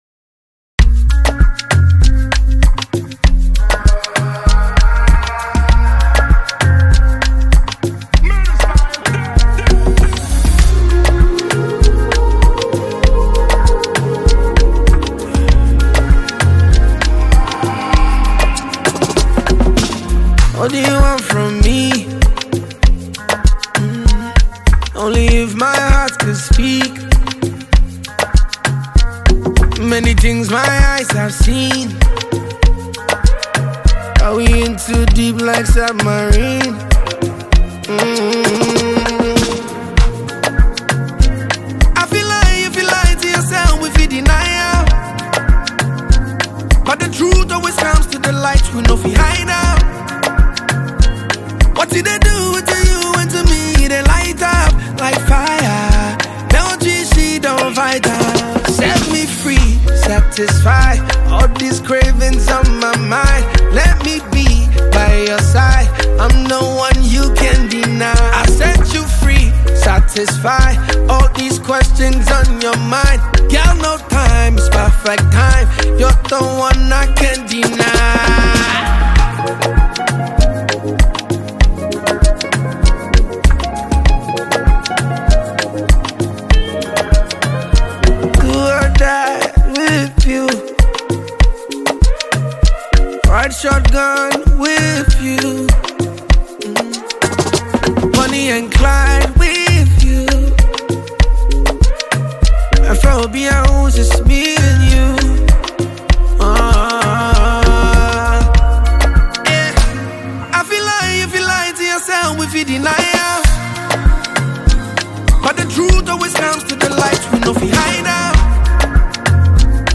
Ghana Music
smooth vocals